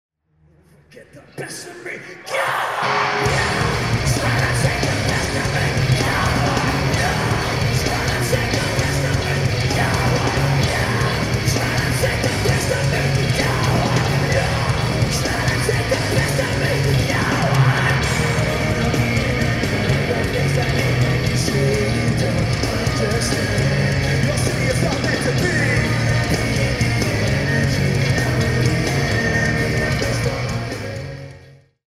Venue:CSU Convocation Center
Venue Type:Arena
Source 1: Audio - AUD (DAT: SP-CMC-7 > Sony TCD-D7)
• Comments: Excellent recording, one of the best from 2004.